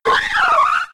Cri de Férosinge K.O. dans Pokémon X et Y.